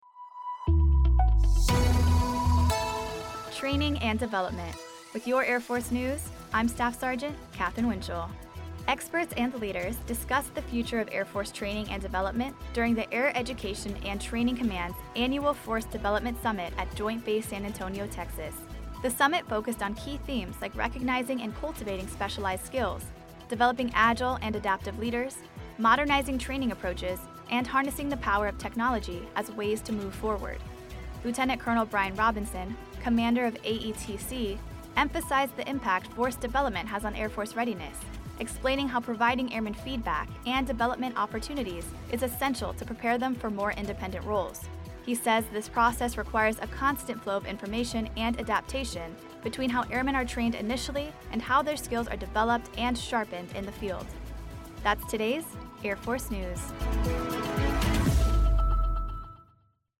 Features audio news stories involving U.S. Air Force technology, personnel, and operations around the globe.